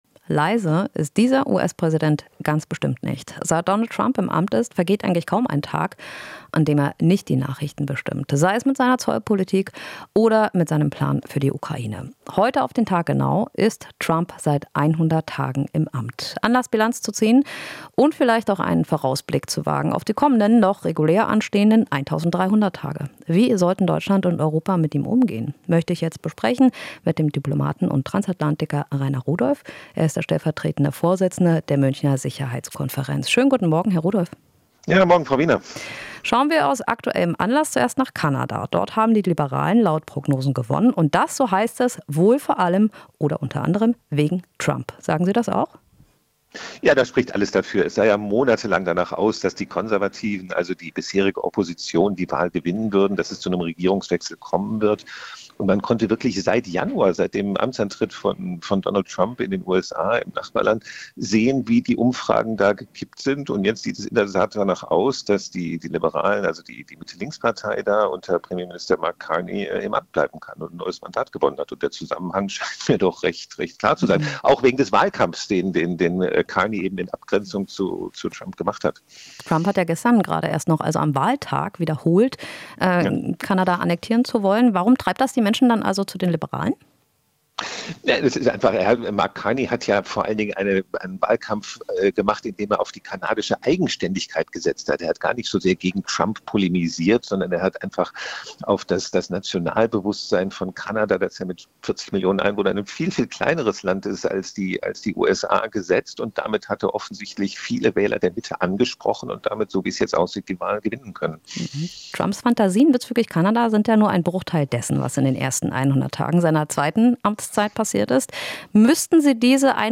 Interview - Diplomat: Für Trump gilt das Recht des Stärkeren